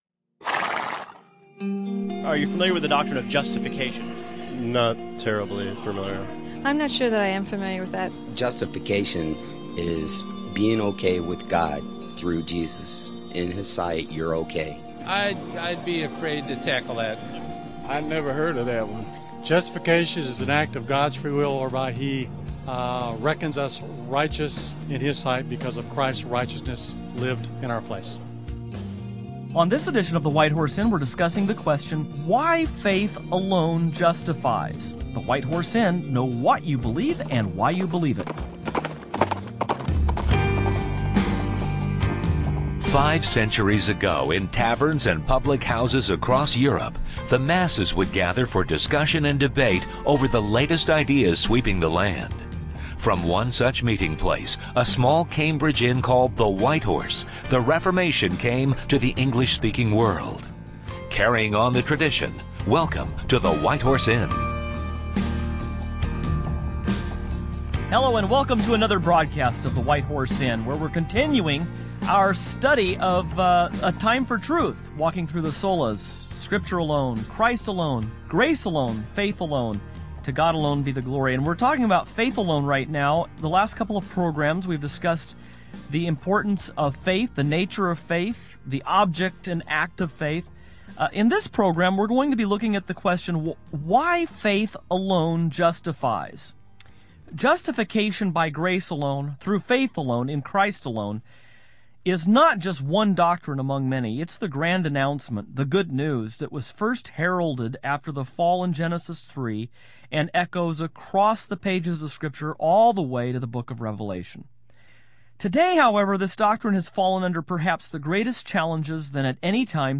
On this edition of the White Horse Inn the hosts continue to unpack the crucial doctrine of justification sola fide, (by faith alone) as they interact with various answers to some on-the-street questions on this important issue, from a recent Christian convention.